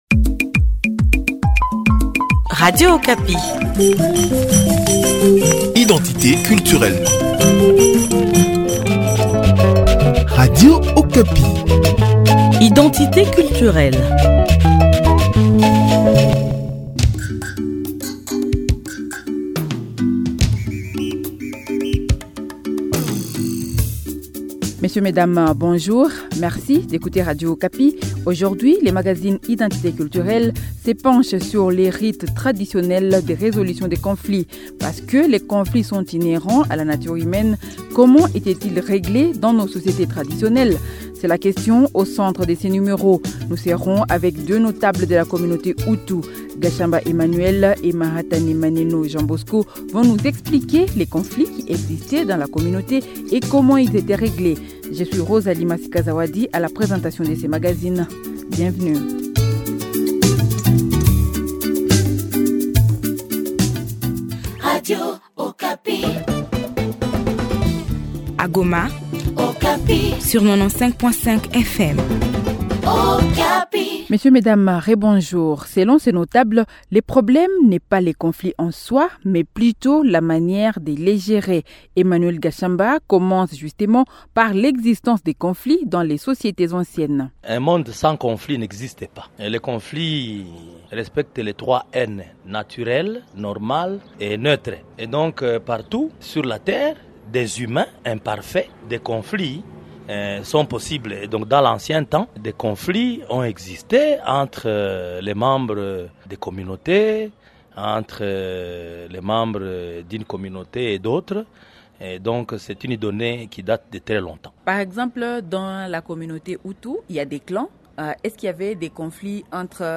Comment les conflits étaient – ils gérés dans les sociétés traditionnelles ? Et comment capitaliser cette sagesse pour bien gérer les conflits actuels ? Dans ce magazine, les notables de la communauté Hutu répondent à ces questions.